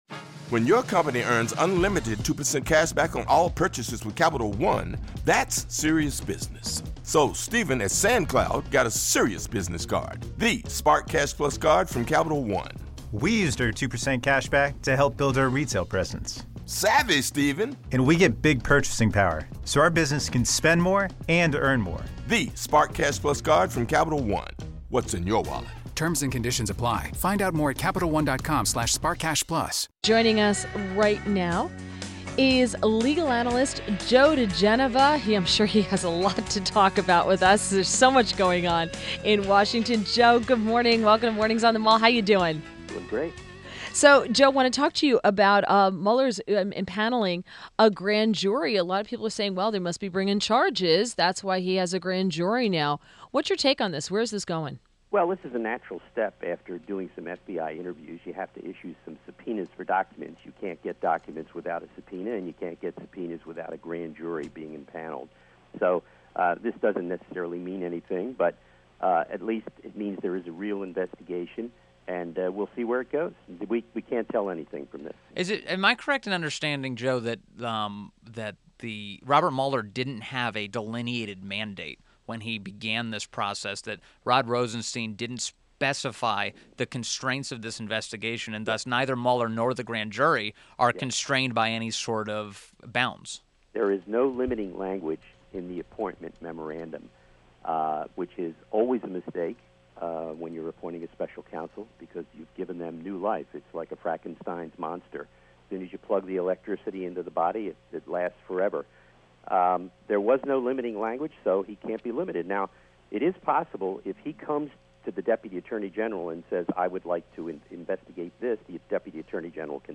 INTERVIEW – JOE DIGENOVA – legal analyst and former U.S. Attorney to the District of Columbia